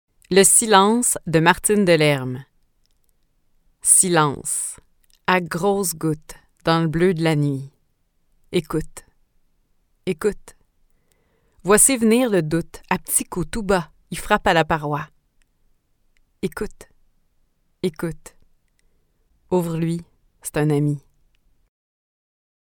SILENCE, VOIX AVEC ACCENT QUÉBÉCOIS (RNS7)
L1_34_P_poeme_RNS7_accentcanadien.mp3